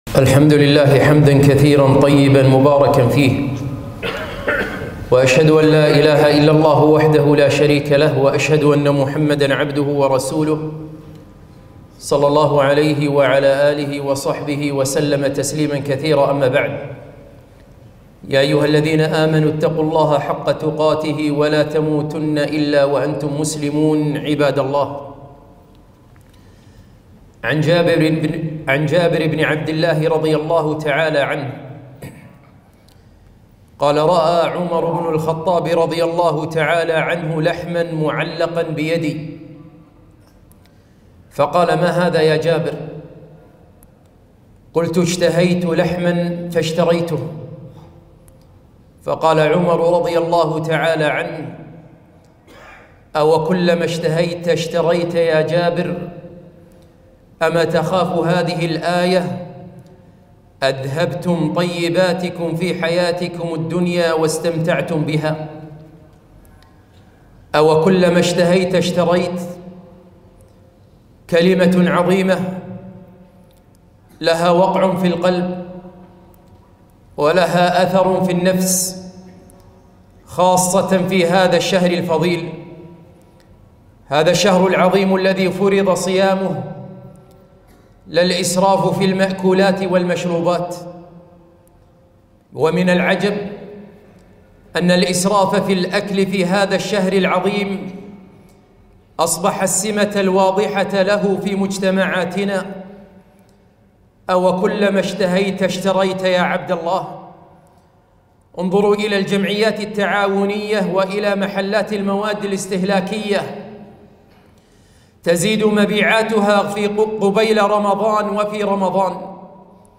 خطبة - أوكلما اشتهيت اشتريت !